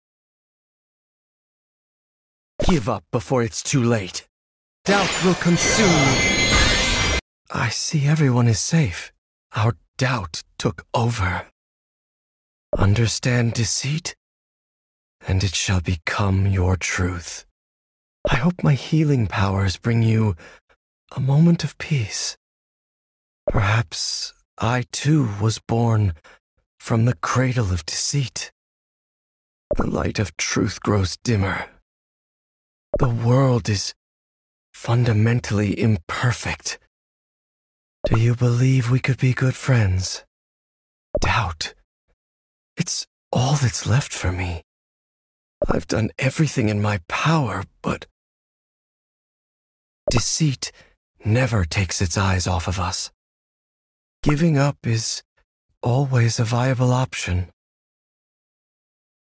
Truthless Recluse English Voicelines (most